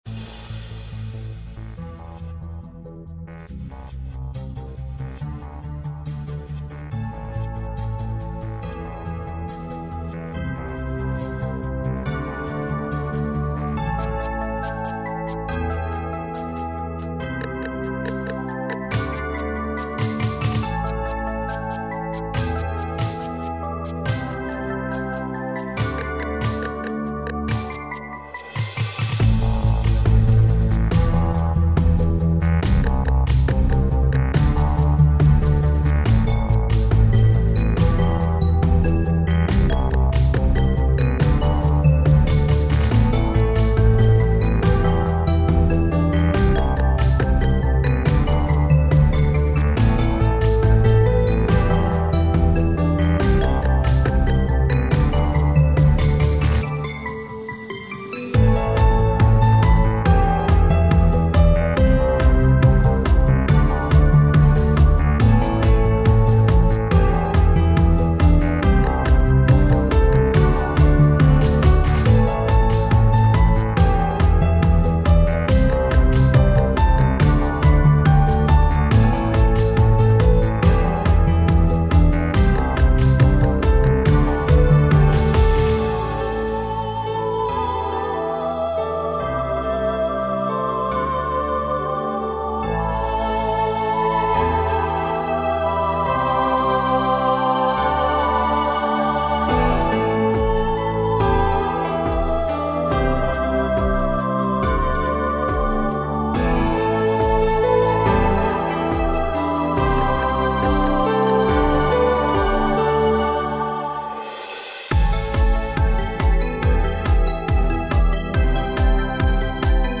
Вокал- сыгран в FL